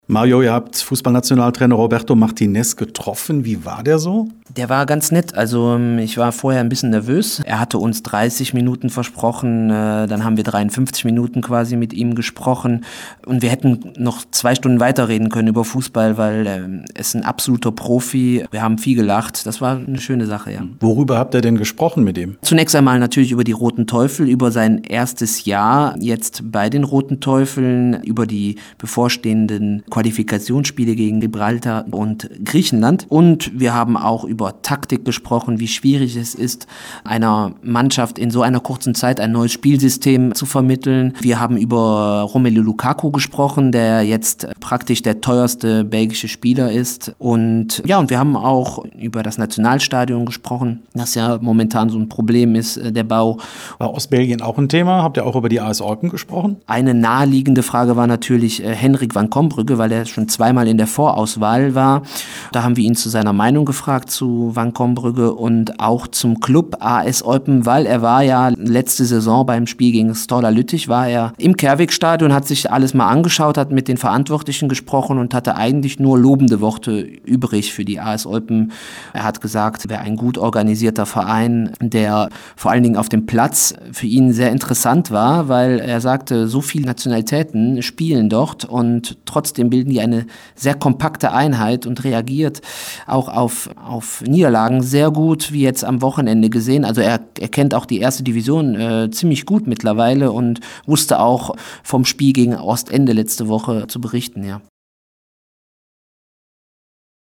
Rote Teufel: Coach Martinez im Interview